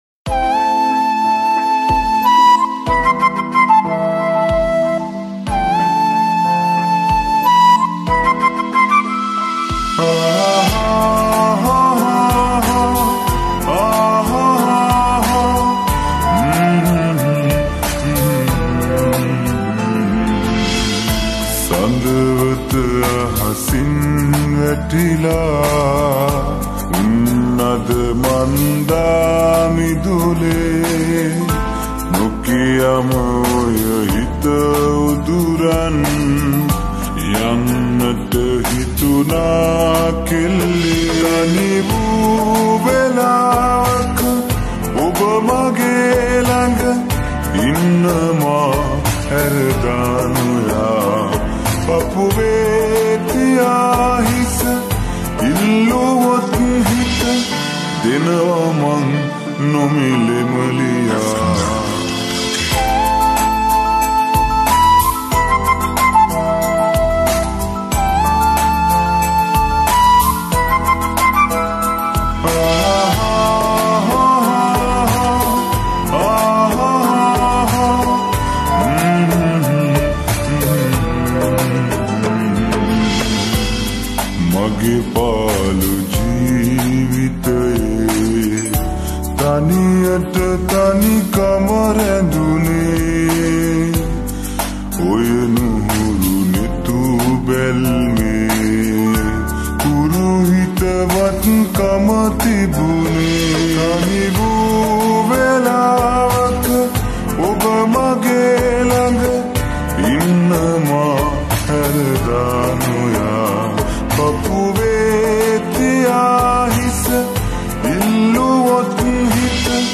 Slowed and Reverb